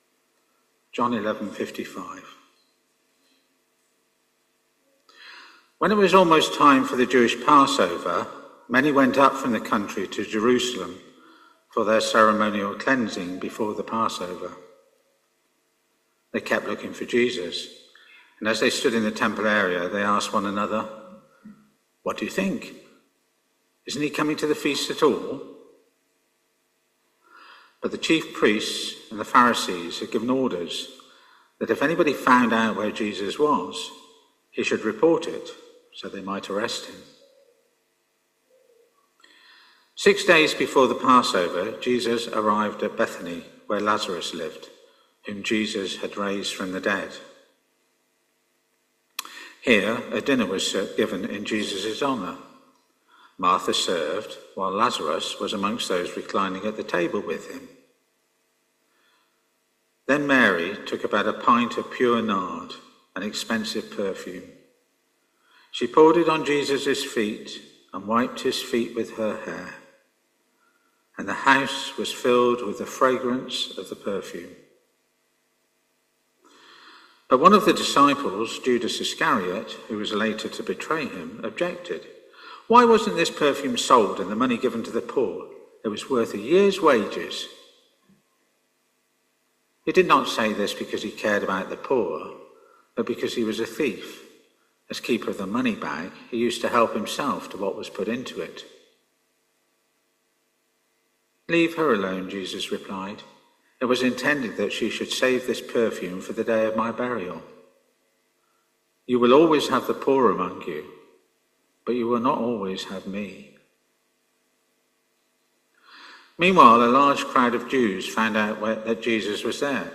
Talk starts after reading at 2.25